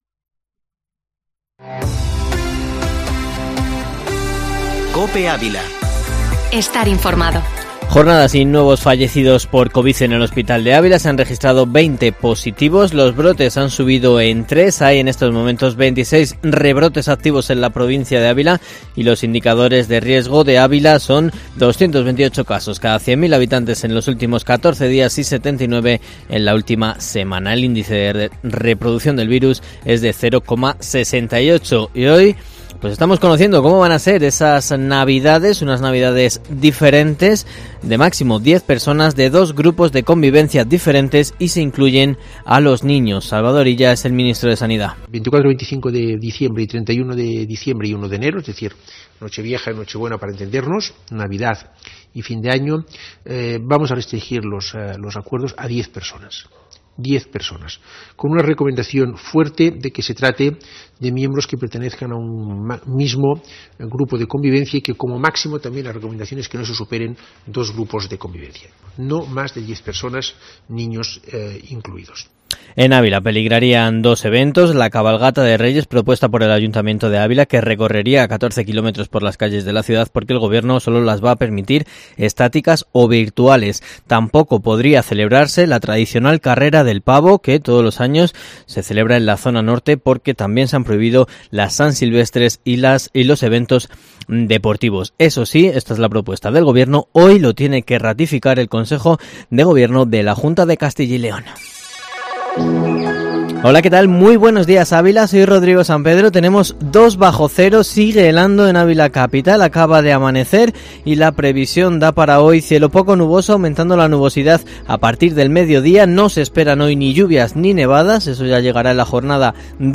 Informativo matinal Herrera en COPE Ávila 03/12/2020